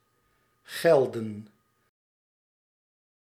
Ääntäminen
IPA: /ɣɛl.də:/